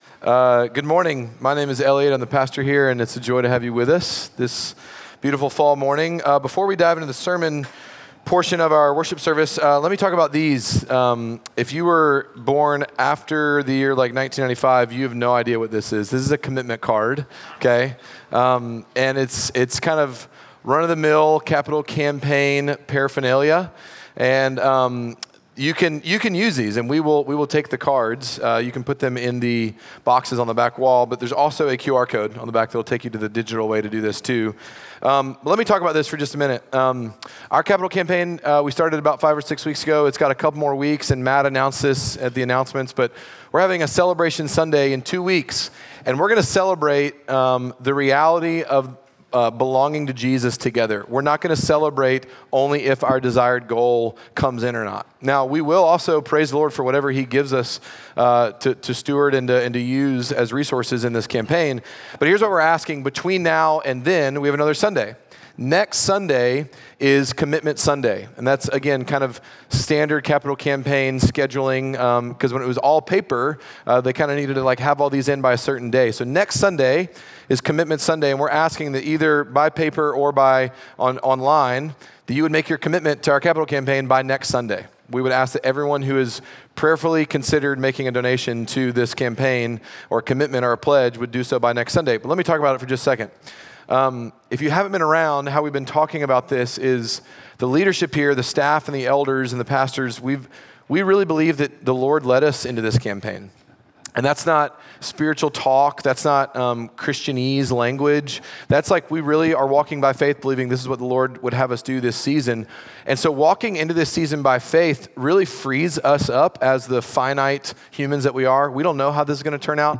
Midtown Fellowship 12 South Sermons To Reach A City Oct 13 2024 | 00:42:55 1x 00:00 / 00:42:55 Subscribe Share Apple Podcasts Spotify Overcast RSS Feed Share Link Embed